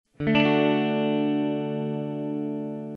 The next chord is an open D chord.
A standard D chord has the notes 1,5,8 (1) and 10 (3).
dmaj.mp3